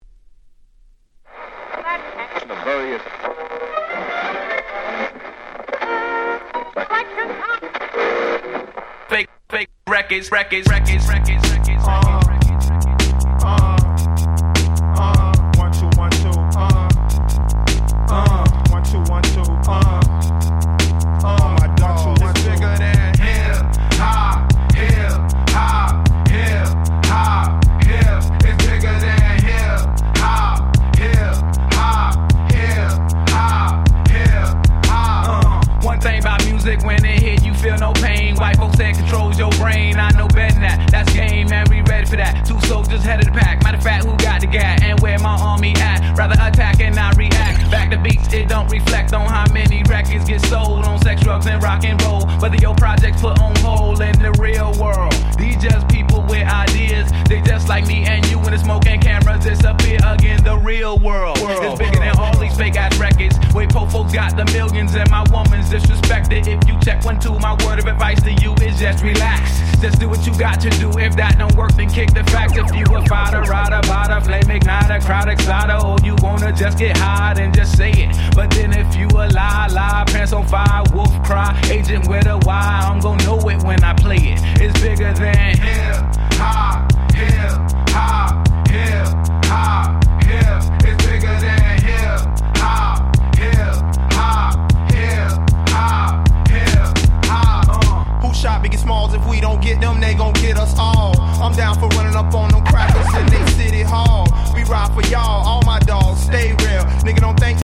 Boom Bap